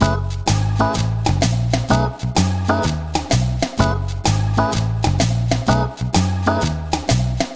Swingerz 5 Full-F#.wav